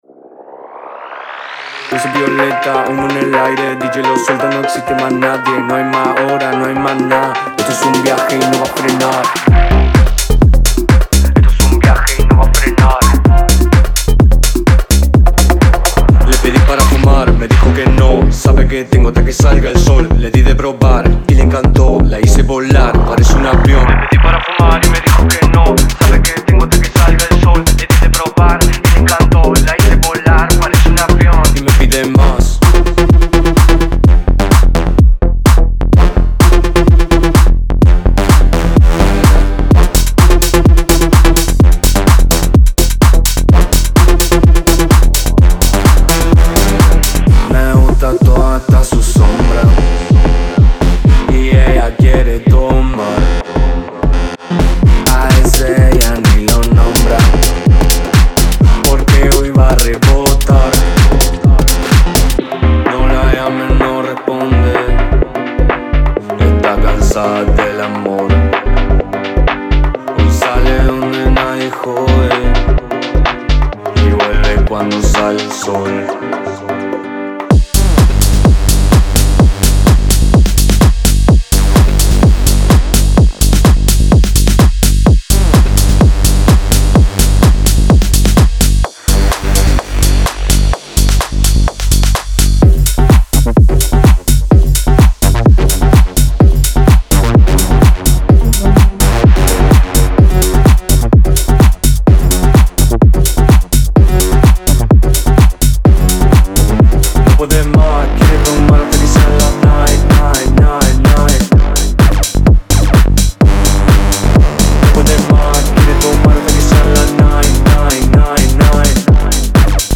デモサウンドはコチラ↓
Genre:Tech House
127 BPM